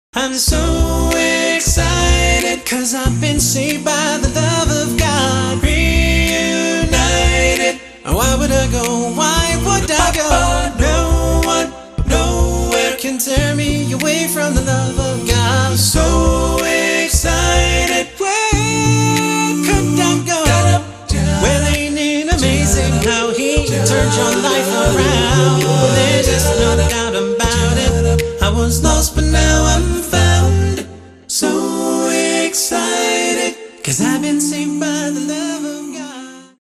Your source for the best in A'cappella Christian Vusic ®